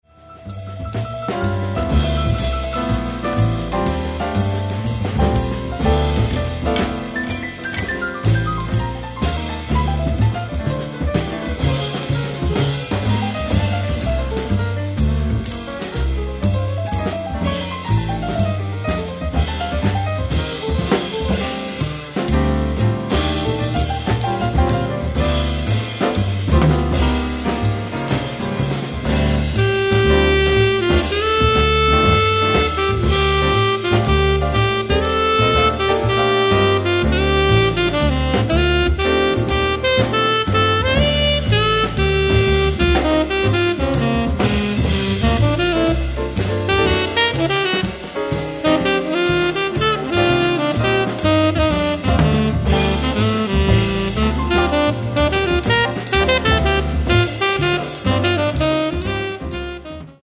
tenor saxophone
acoustic bass
drums
piano
trumpet